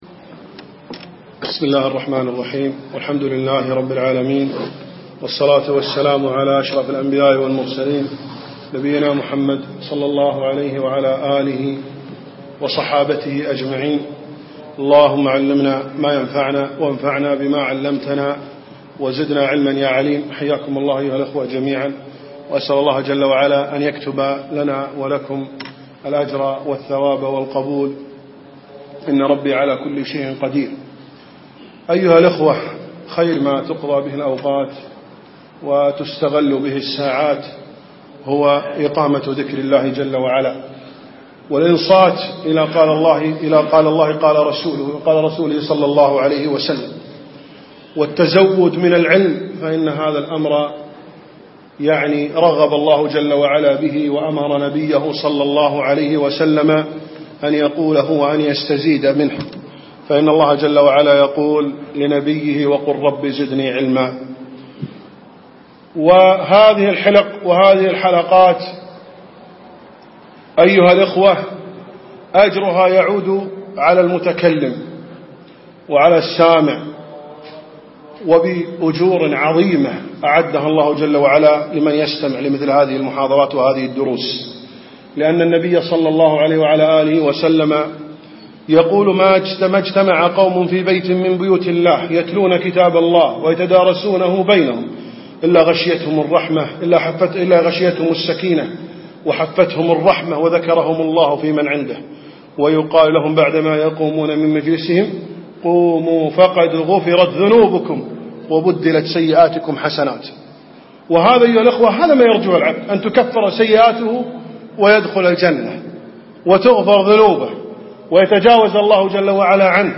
يوم الأثنين20 ربيع ثاني 1436 الموافق 9 2 2013 منطقة المهبولة مسجد الحمادي